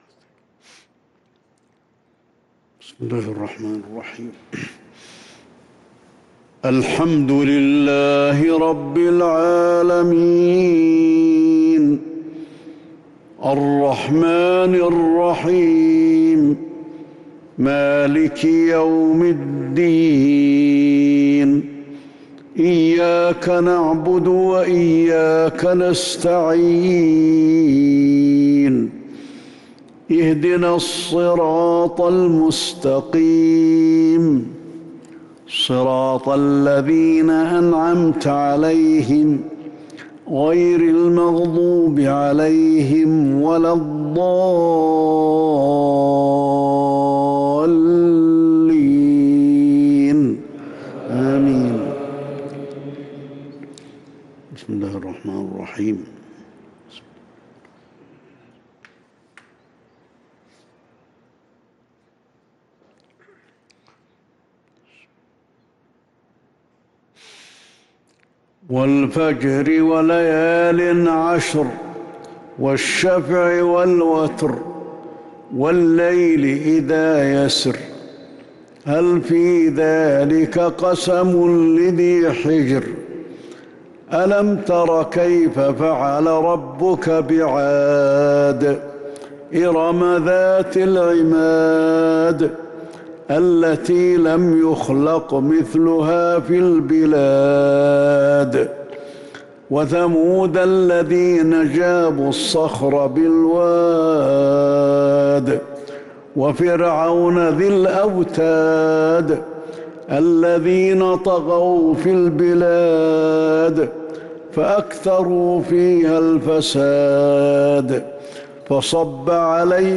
صلاة العشاء للقارئ علي الحذيفي 1 جمادي الأول 1443 هـ
تِلَاوَات الْحَرَمَيْن .